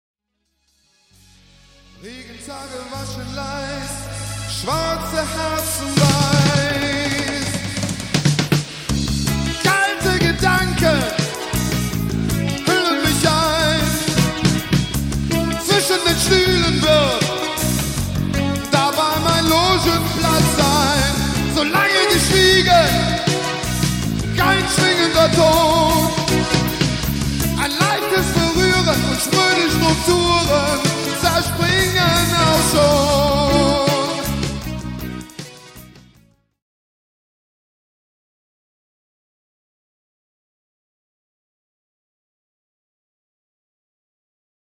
sax
voc
Aufnahme: 1985, Krefeld